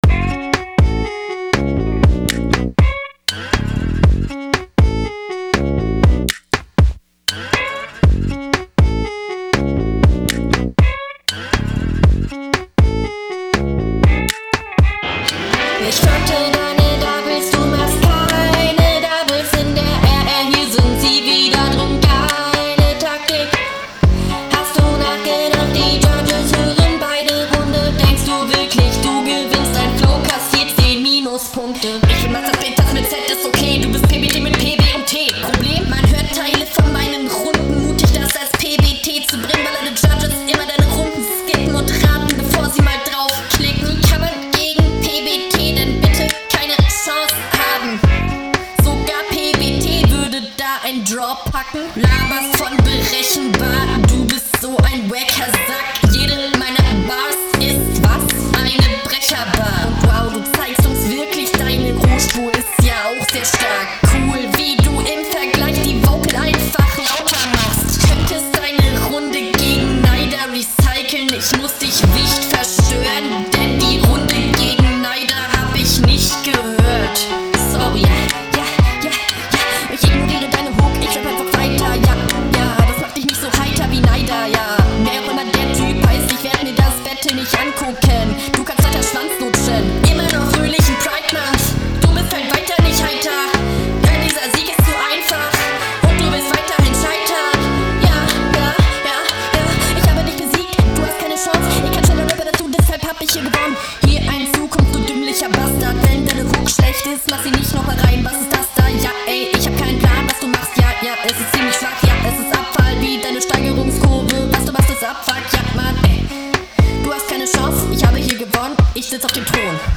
hook eccht müll aber sonst ganz ok